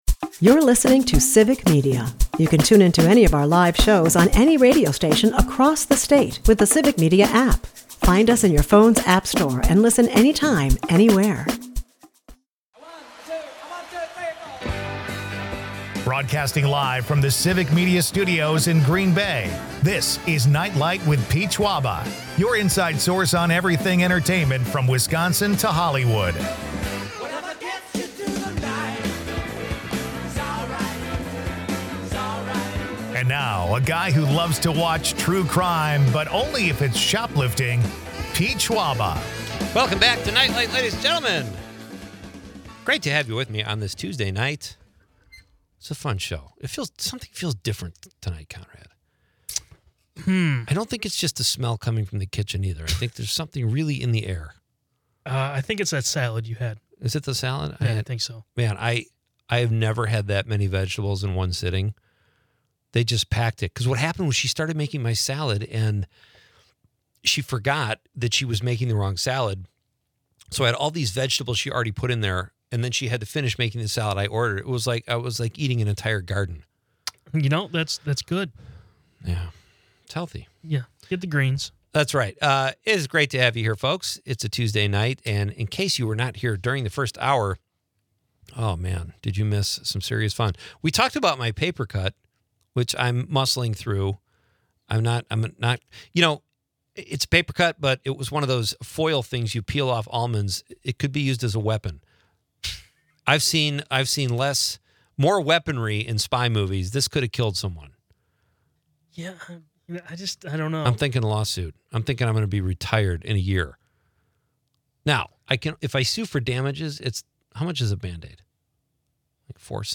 The episode brims with Robert Redford movie nostalgia and features clips from 'The Sting' and 'Butch Cassidy.'